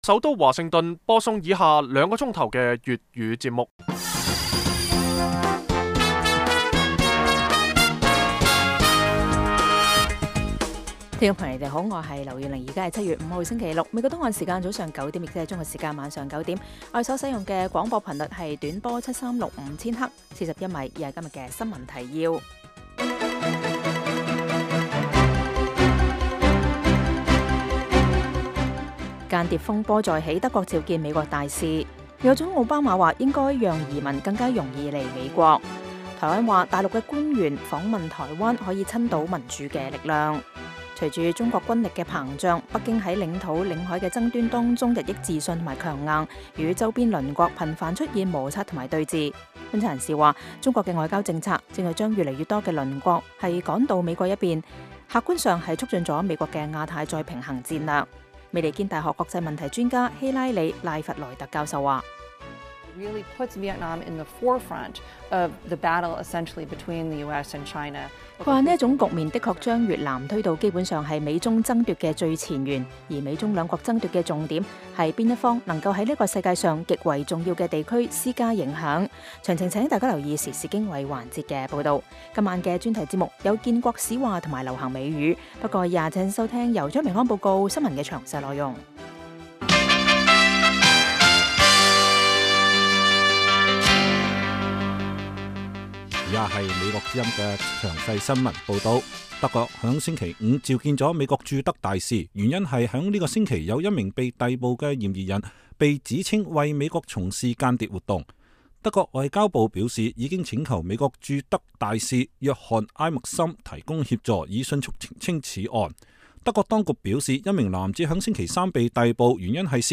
每晚 9點至10點 (1300-1400 UTC)粵語廣播，內容包括簡要新聞、記者報導和簡短專題。